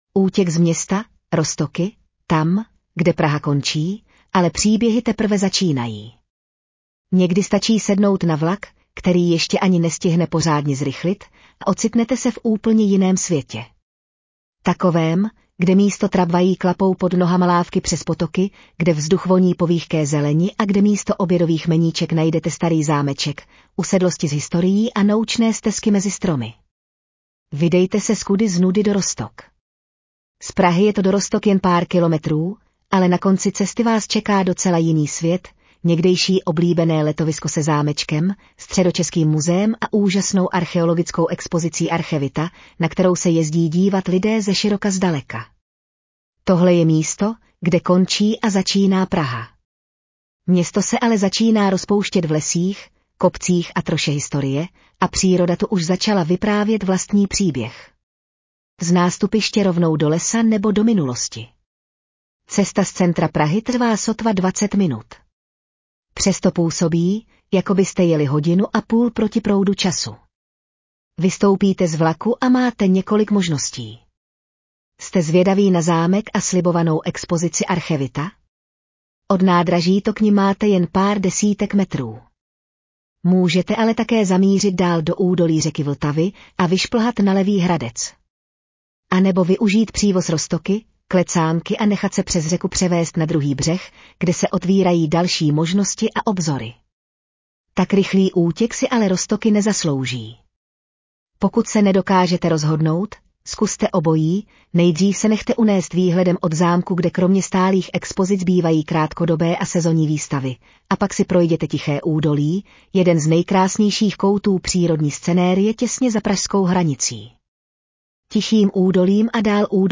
15462vlastaneural.mp3